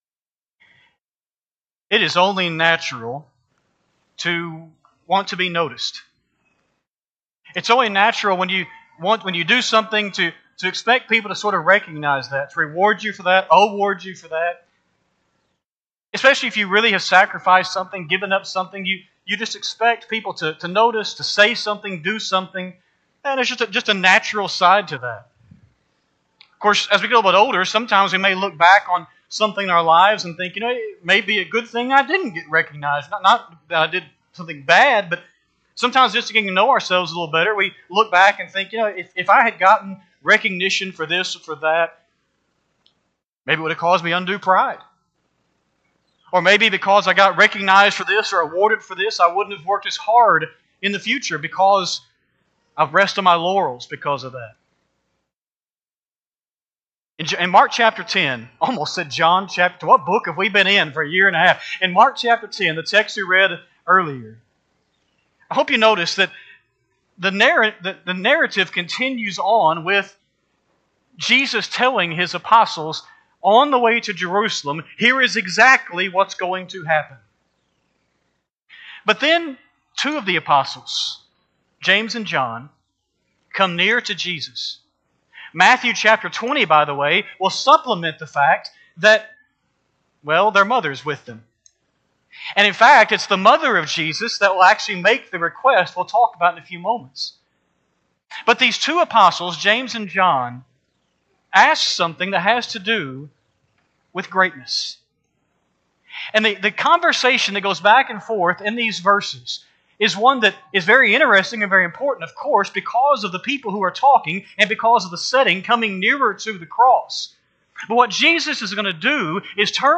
Sunday AM Sermon
5-5-24-Sunday-AM-Sermon.mp3